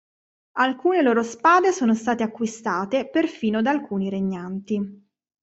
Read more Prep Verb Frequency A1 Pronounced as (IPA) /da/ Etymology From Latin dē (“from”) + ā/ab (“of, from”).